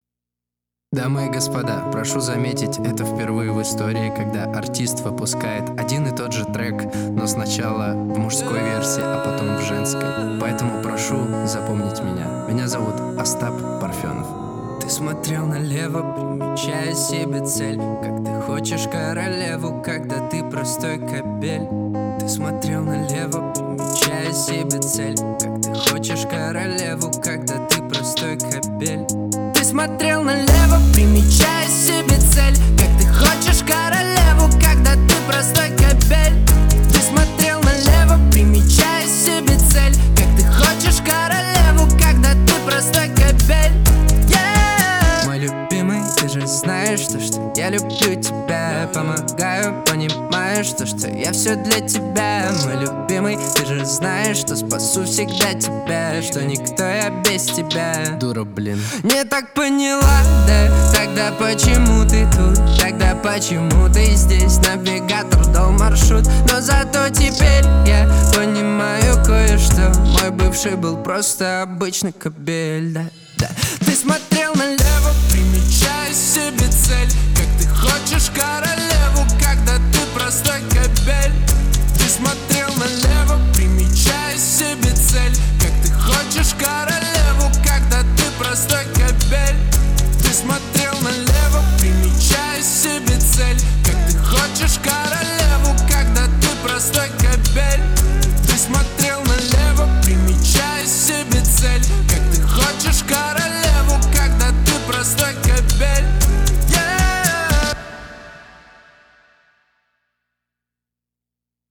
женская версия